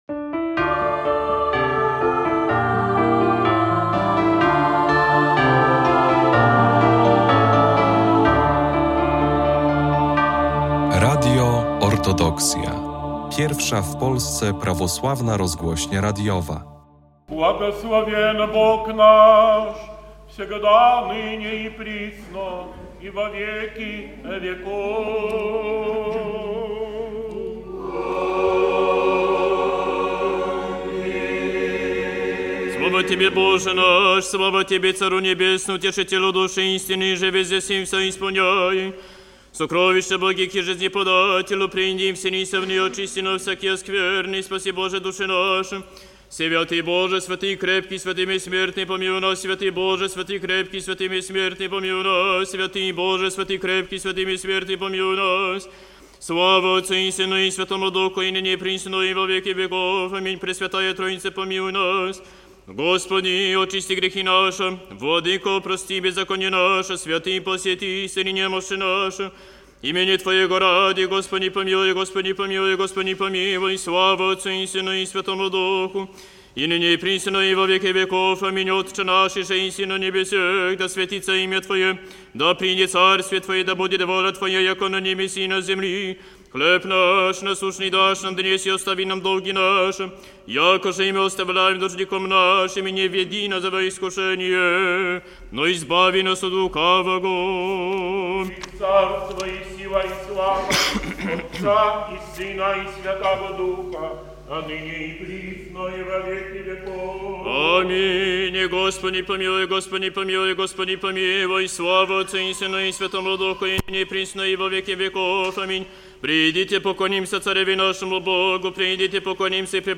Nabożeństwo pasyjne z czytaniem Ewangelii według św. Jana
30 marca 2025 roku w katedrze św. Mikołaja Cudotwórcy w Białymstoku Jego Ekscelencja Arcybiskup Białostocki i Gdański Jakub przewodniczył wielkiej wieczerni z czytaniem ewangelii o mękach i cierpieniach Jezusa Chrystusa według ewangelisty Jana. Zapraszamy do wysłucha retransmisji nabożeństwa.